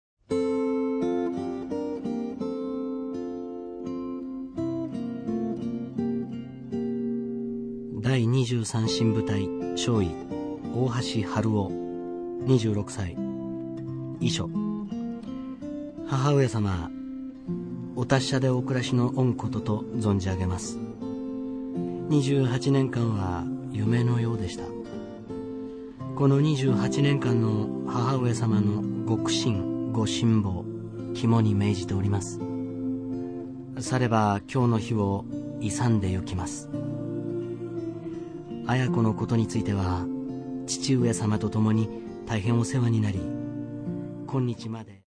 和の雰囲気があふれる佳曲ばかりで